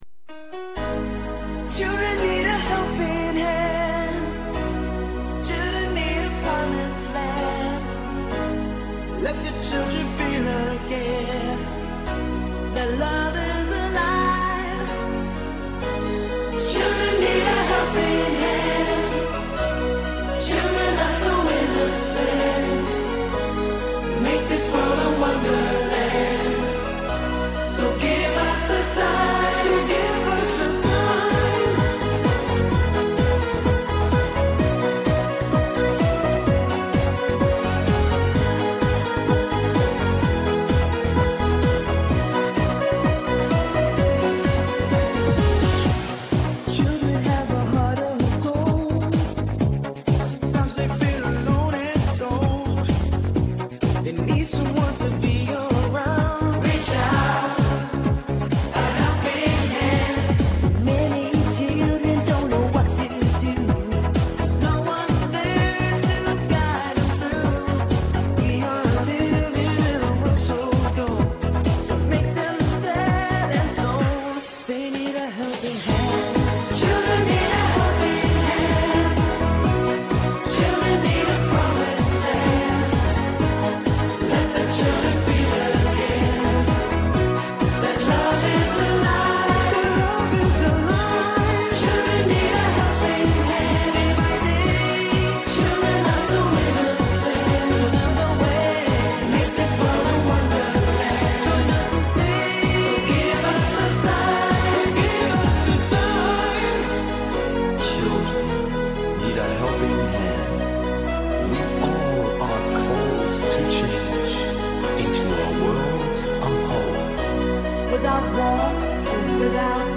Charity Concert